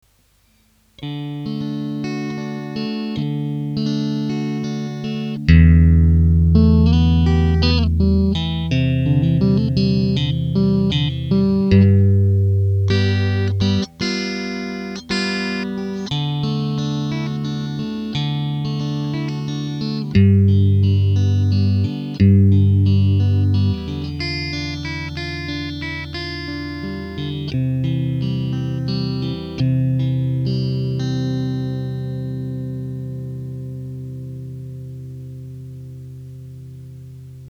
so kling sie nach dem Umbau (über XVamp ohne Effekte, Amp- oder Speakersimulation direkt in die Soundkarte).
Der Sound der Gitarre ändert sich weder durch das Abschirmen, noch durch das Wachsen (d.h. wem der Klang nicht gefällt kann sich die Arbeit sparen oder muss zusätzlich neue Pickups ausprobieren), aber die Reduktion im Brummen und in der Feed-Back-Anfälligkeit ist enorm.